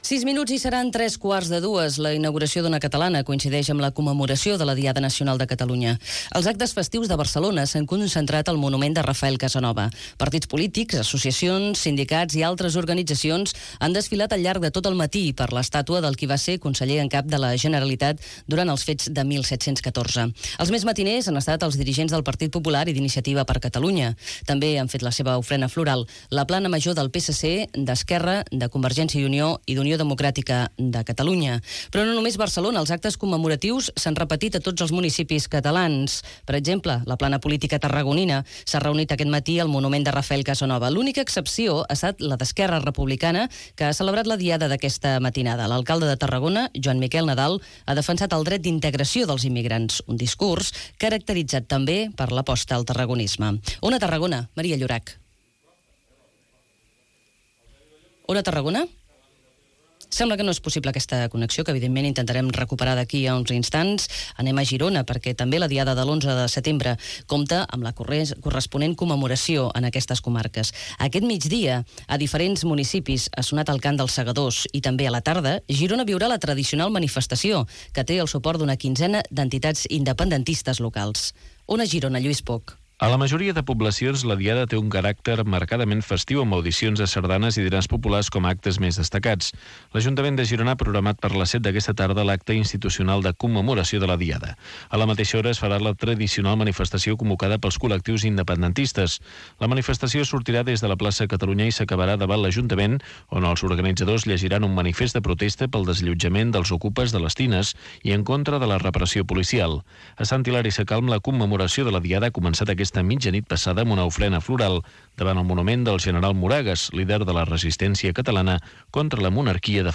Hora, roda de connexions amb les emissores sobre la Diada de l'11 de setembre: Ona Girona, Ràdio Costa Brava, Ona Tarragona, Ràdio Pirineus i Ona Catalana Perpinyà.
Informatiu